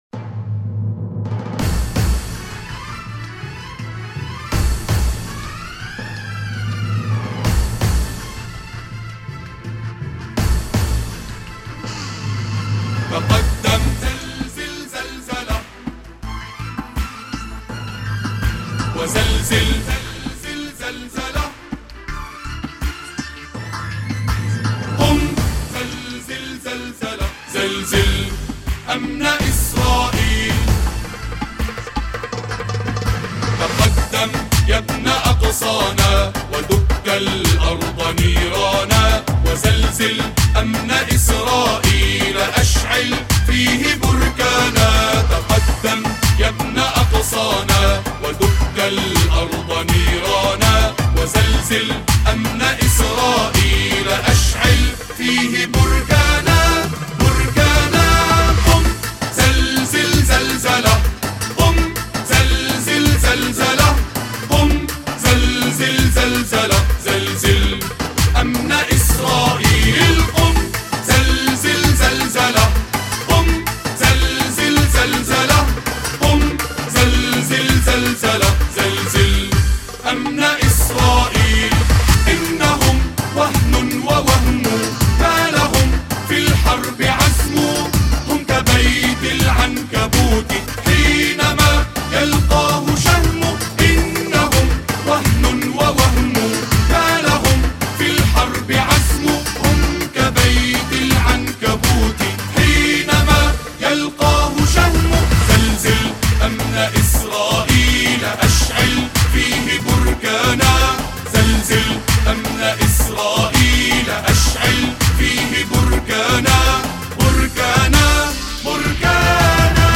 أناشيد فلسطينية... أمن إسرائيل